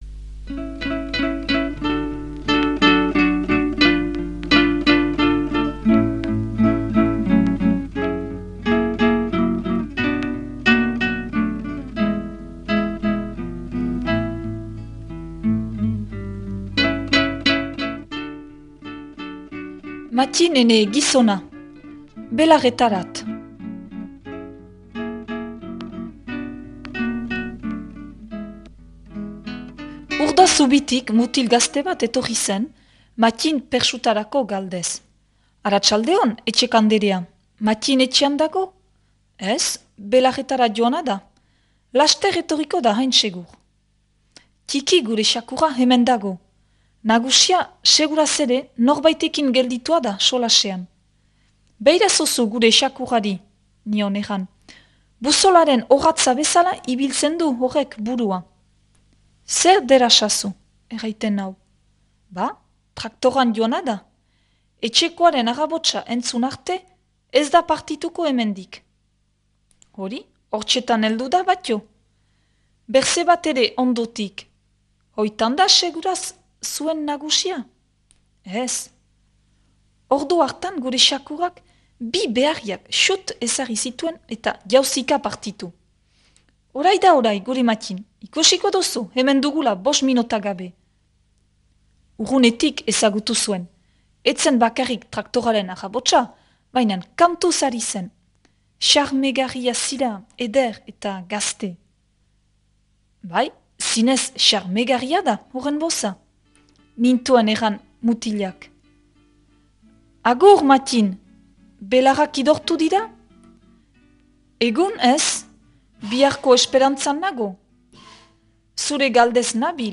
proposatu irakurketa da.